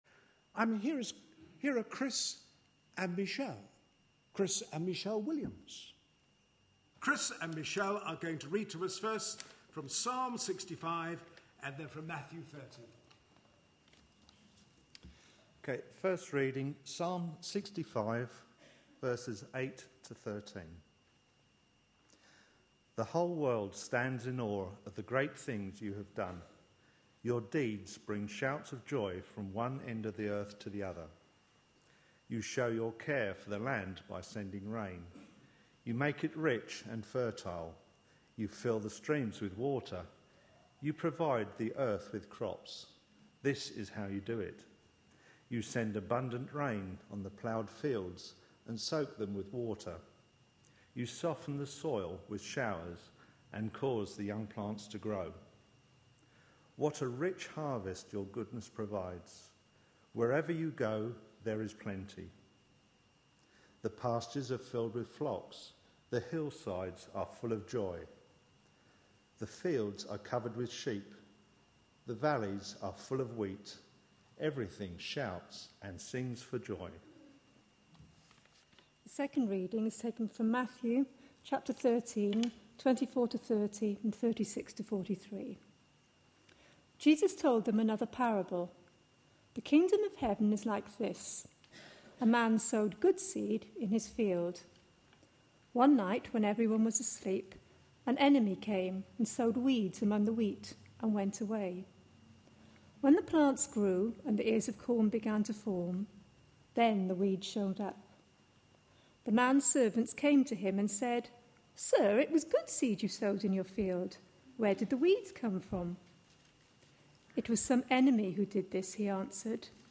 A sermon preached on 23rd September, 2012.